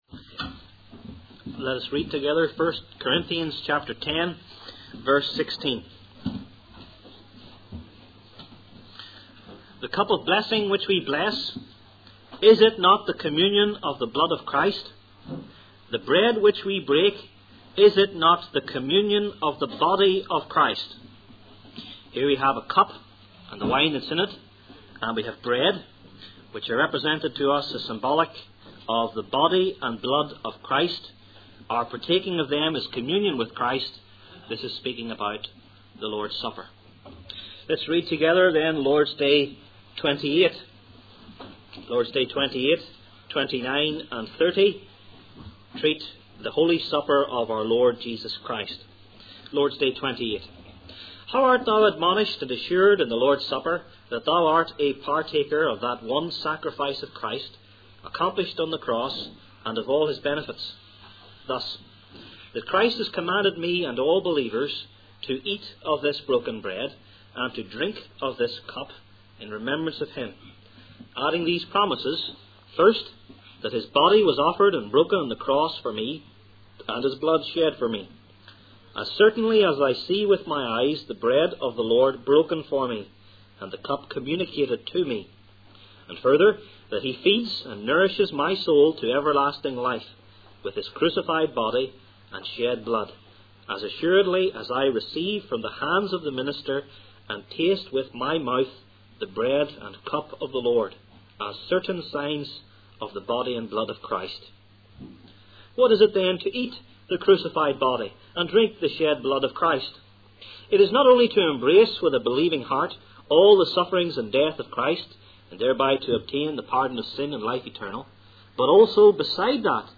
Heidelberg Catechism Sermons